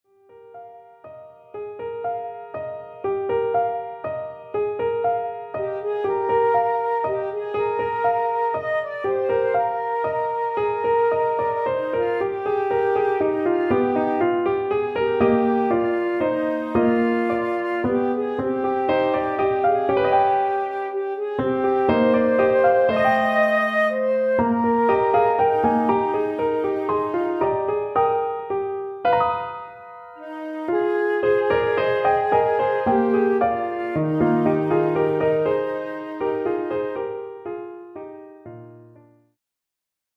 Australian choral music
subtle and tuneful accompaniment
Genre : Lyrical Suitable for